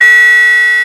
RetroGamesSoundFX / Alert / Alert09.wav
Alert09.wav